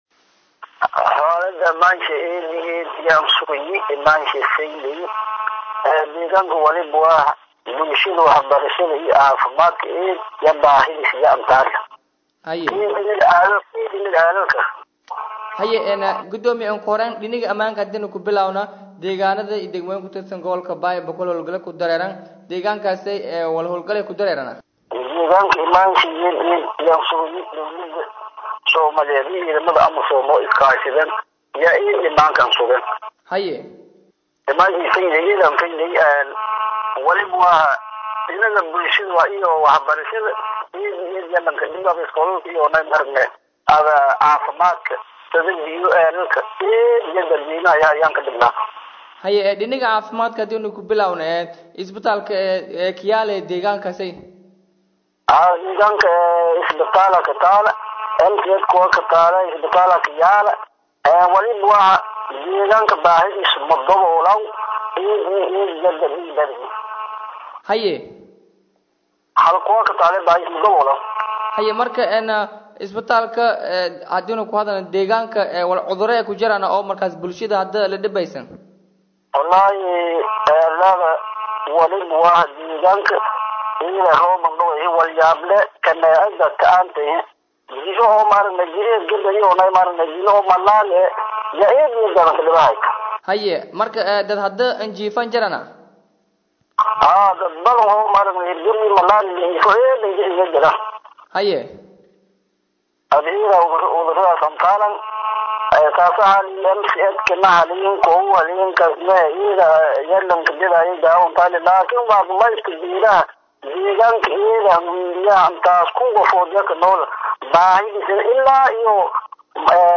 Gudoomiyaha-Degaanka-G-Shabeello-Xuseen-Wardheere-Xasan-.mp3